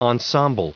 Prononciation du mot ensemble en anglais (fichier audio)
Prononciation du mot : ensemble